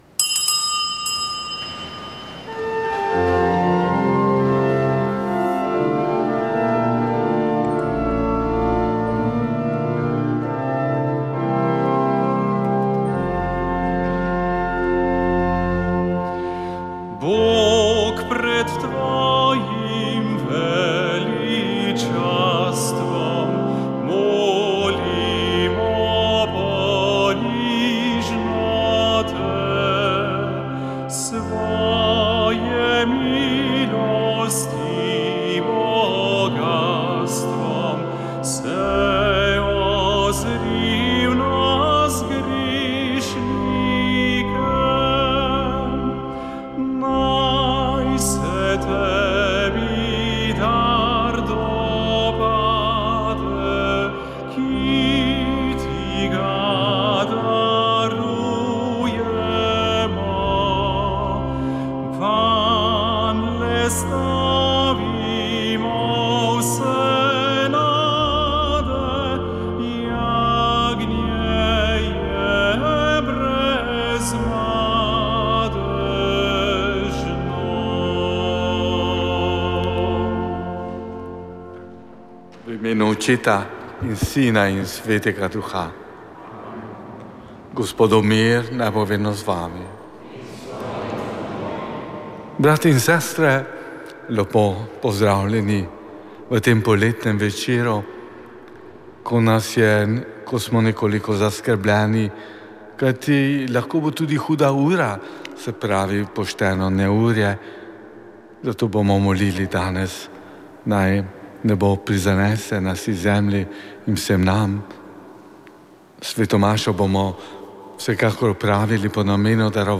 Sv. maša iz cerkve sv. Marka na Markovcu v Kopru 31. 1.